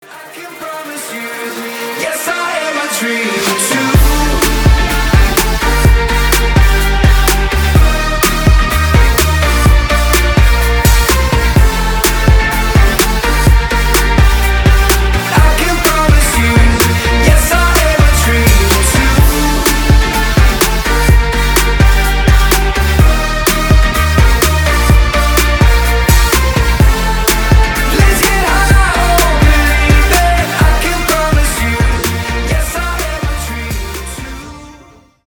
мужской вокал
громкие
dance
club
progressive house
house